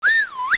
notification_agenda.wav